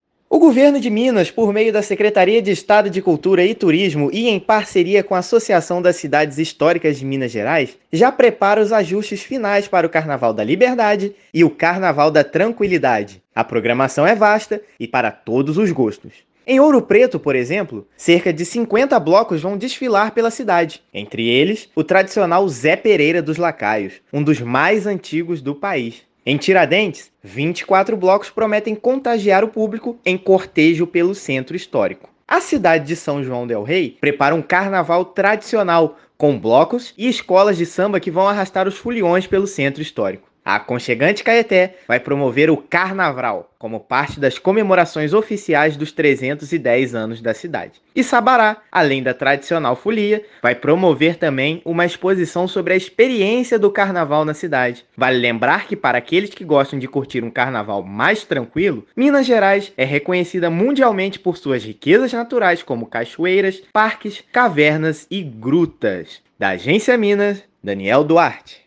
Programação chama a atenção pela diversidade de atrações, belezas naturais e cardápios típicos que agradam foliões de todas as tribos. Ouça matéria de rádio.